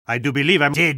spy_paincrticialdeath03.mp3